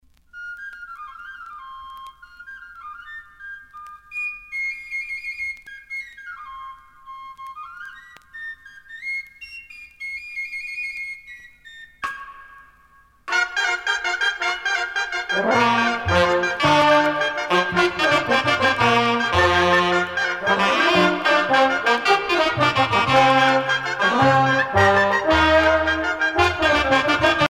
2 fiscorns
danse : sardane
Pièce musicale éditée